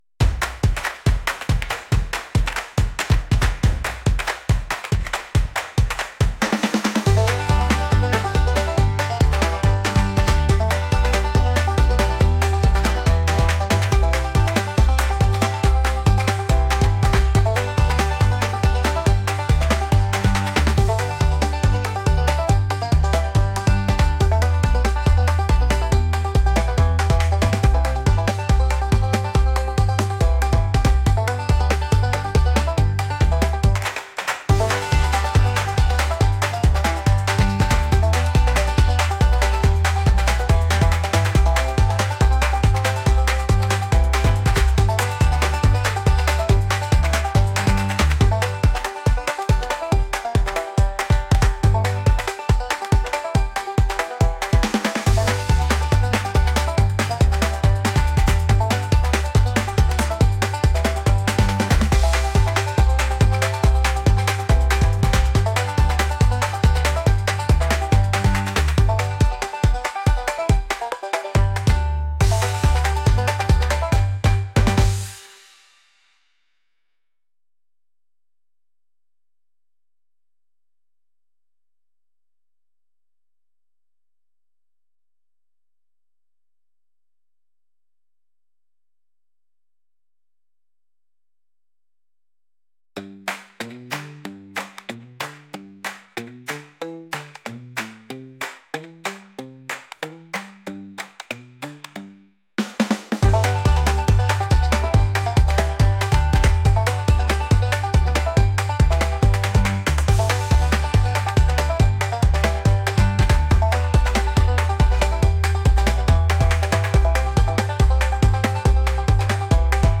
lively